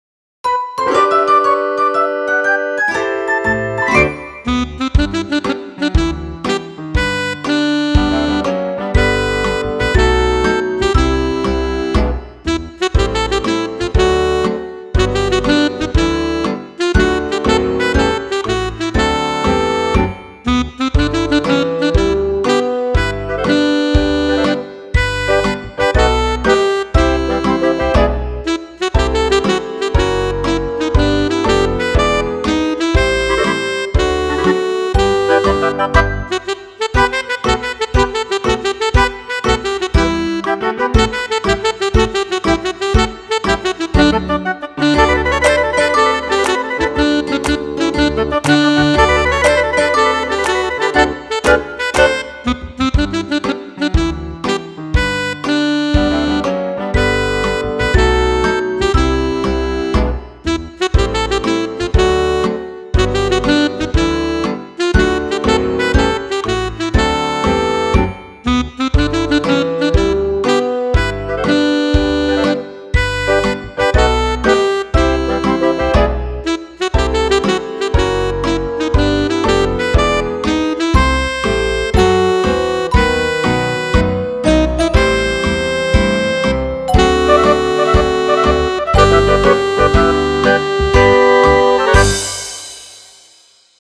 Okay, back to the basics and simple arrangements.